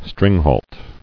[string·halt]